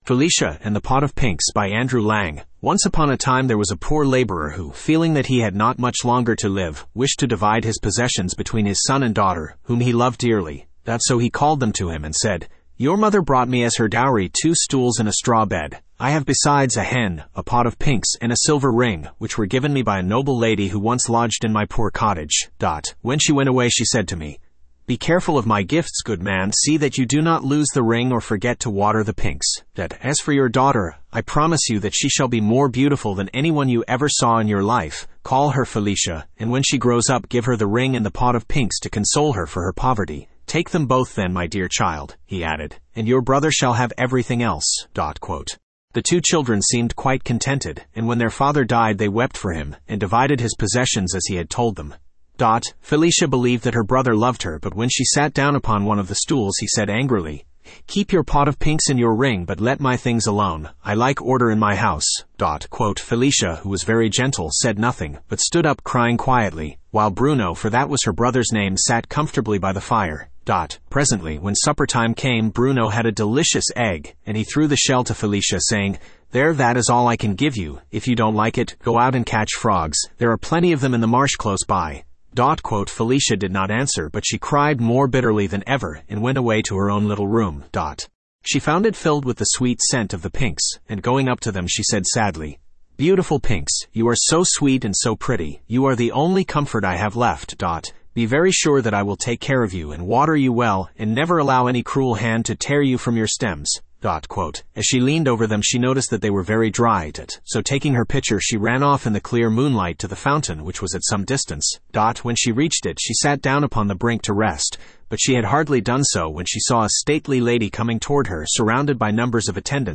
Studio (Male)